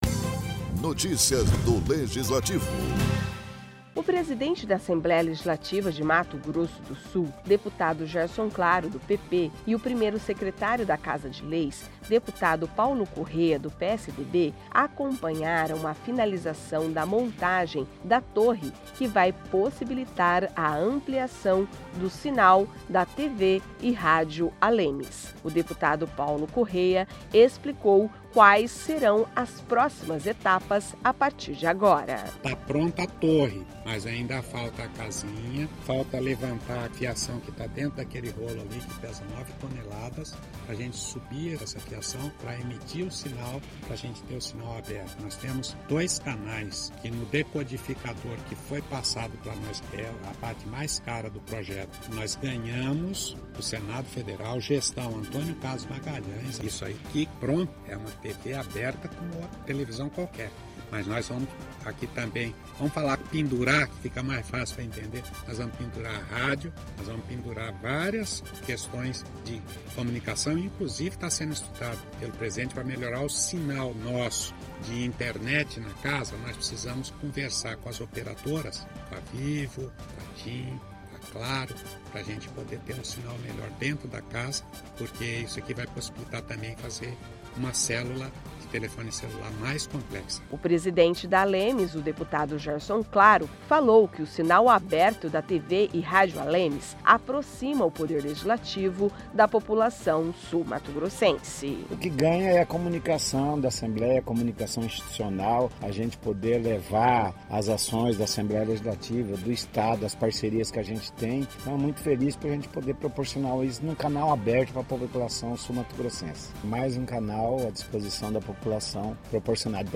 O presidente da Assembleia Legislativa de Mato Grosso do Sul (ALEMS), deputado Gerson Claro (PP), e o primeiro secretário da Casa de Leis, deputado Paulo Corrêa (PSDB), acompanharam a finalização da montagem da torre que vai possibilitar a ampliação do sinal da TV e Rádio ALEMS. O deputado Paulo Corrêa explicou quais serão as próximas etapas a partir de agora.